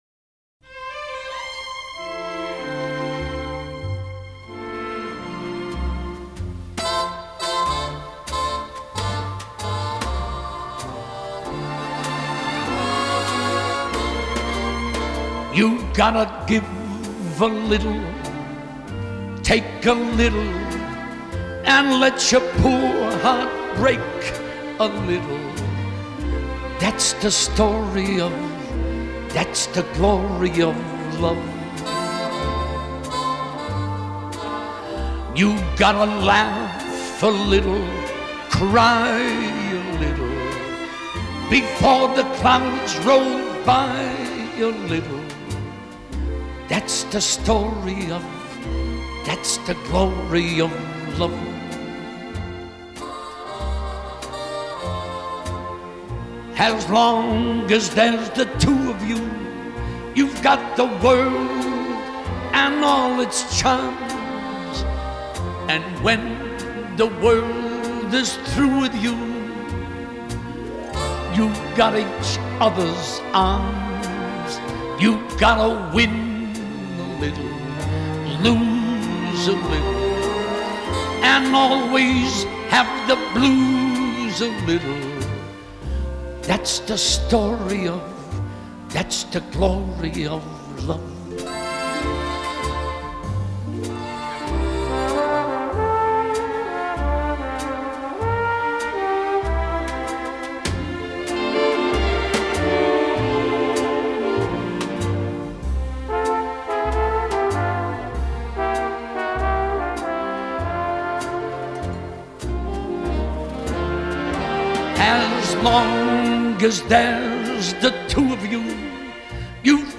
1936   Genre: Pop   Artist